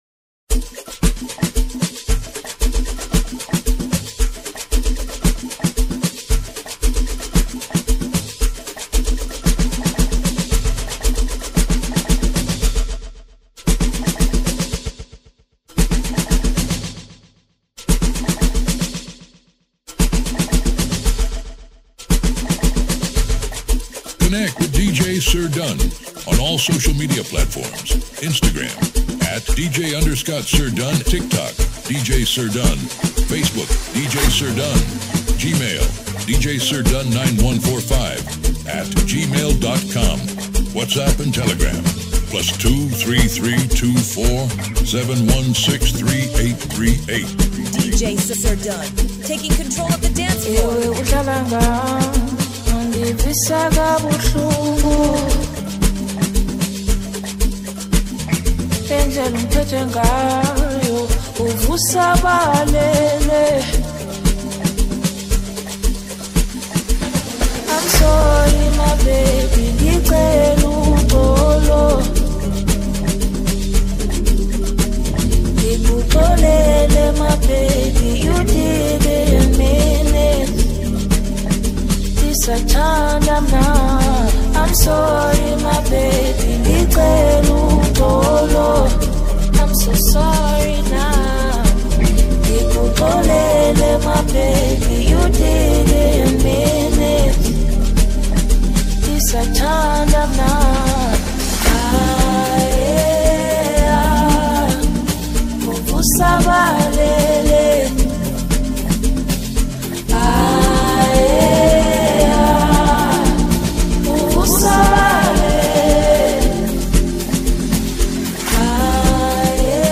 This is a banger all day.